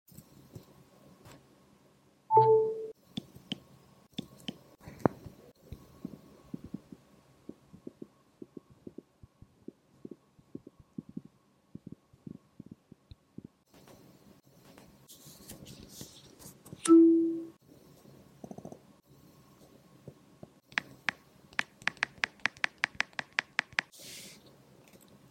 🔊 iPhone 16 Pro Max Noises - Which One’s Your Favorite?